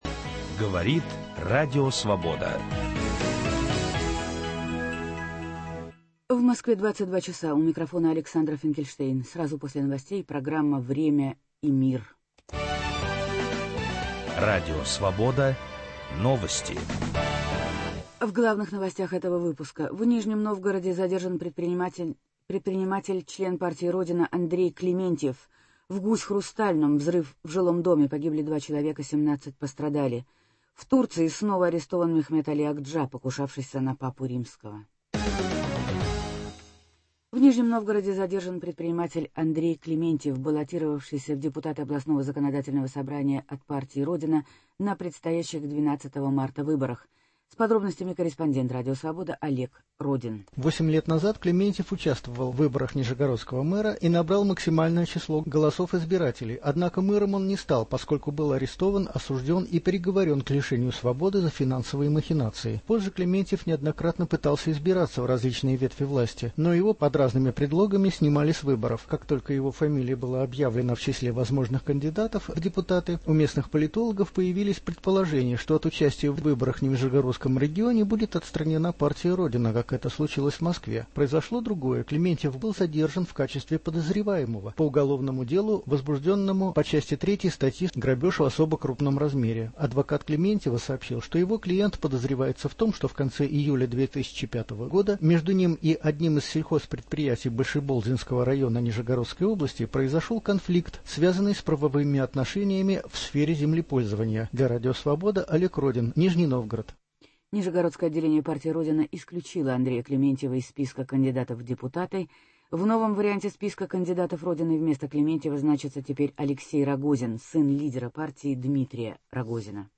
Аналитический радиожурнал